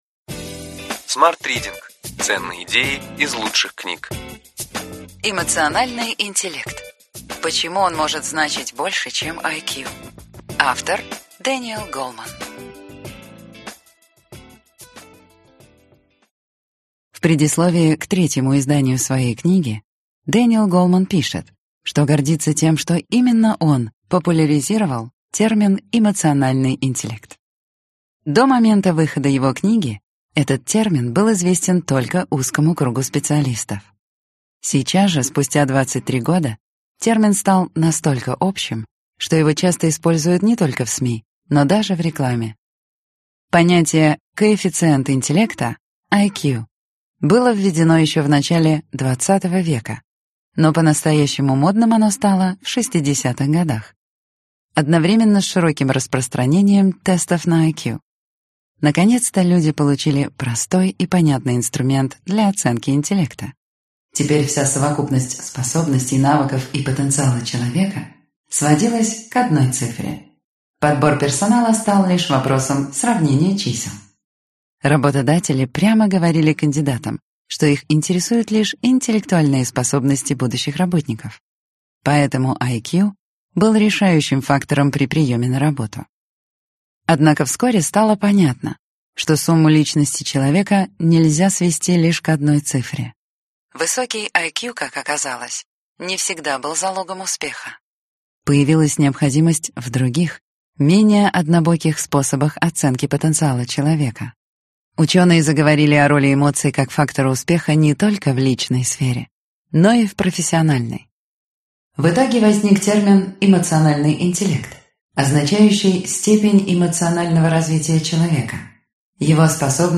Аудиокнига Год личной эффективности. Сборник 3 | Библиотека аудиокниг